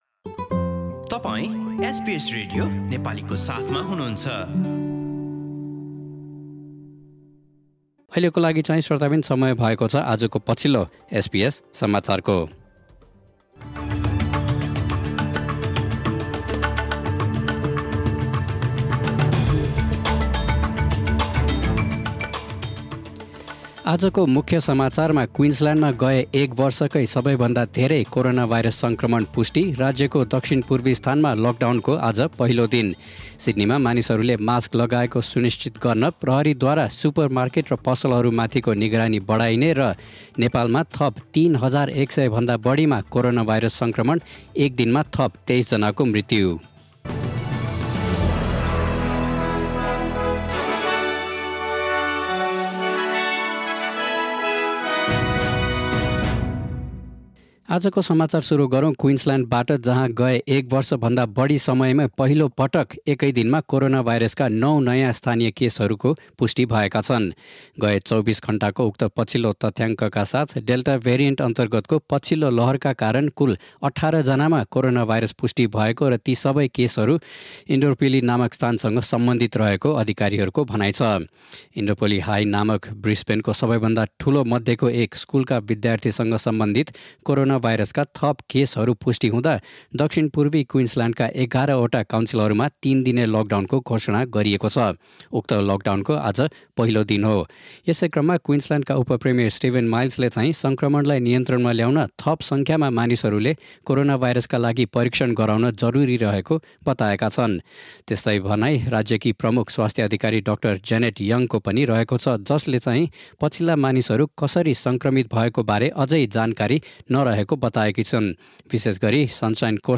Listen to the latest news headlines from Australia in Nepali.